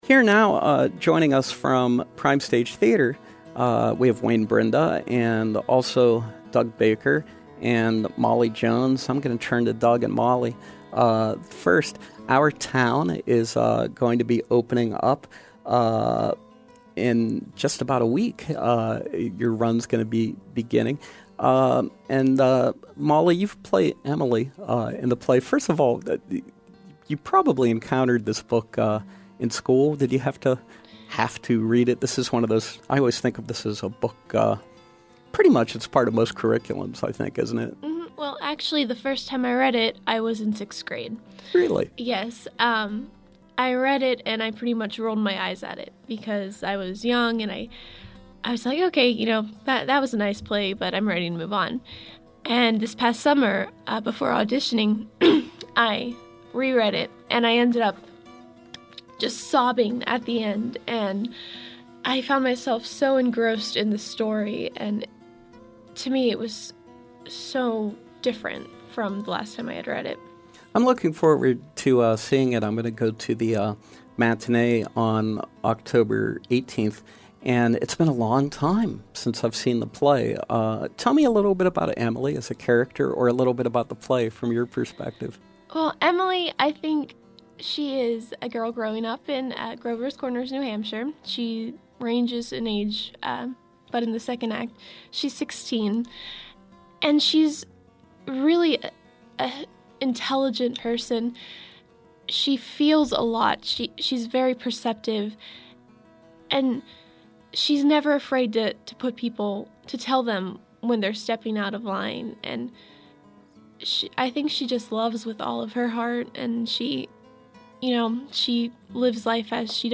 stop by our studio to read a scene from the play.